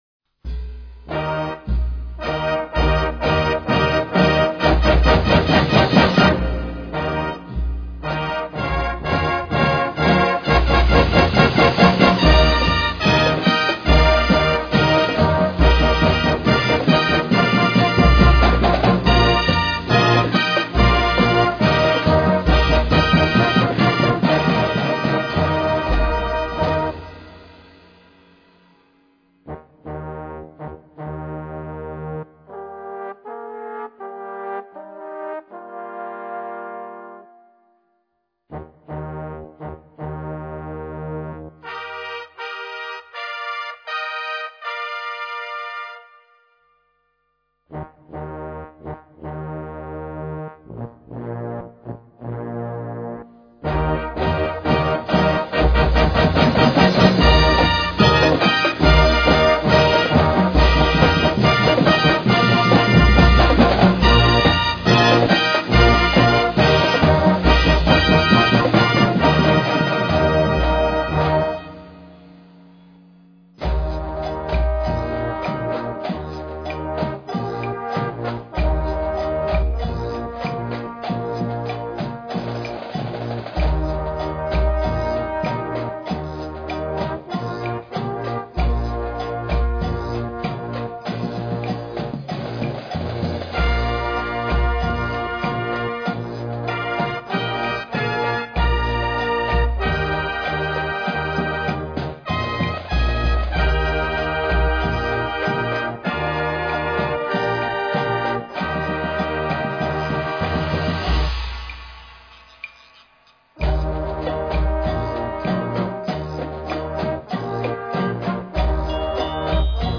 Besetzung: Naturtonfarenzug.
Musik für Naturton-Fanfarenzüge